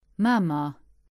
Mamer (Luxembourgish pronunciation: [ˈmaːmɐ]
Lb-Mamer.ogg.mp3